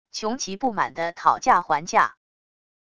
穷奇不满地讨价还价wav音频